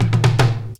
04DR.BREAK.wav